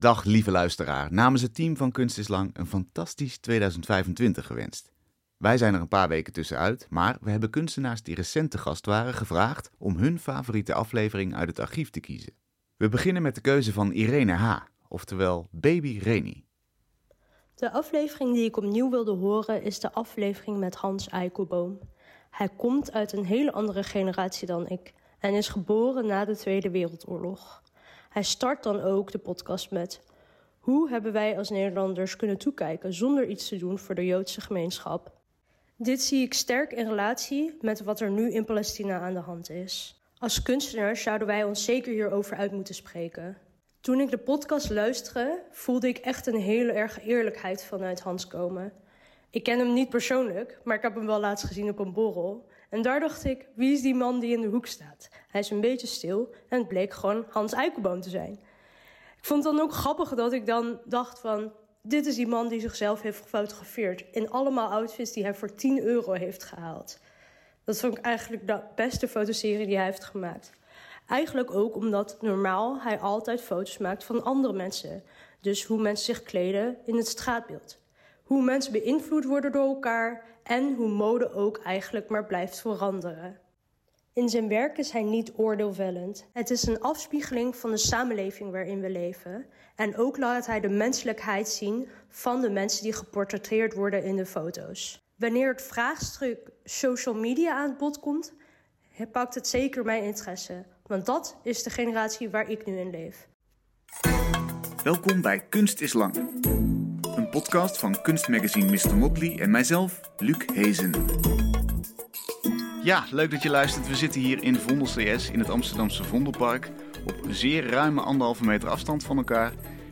Je hoort elke week een uitgebreid gesprek met een kunstenaar over het laatste werk, inspiratiebronnen en drijfveren.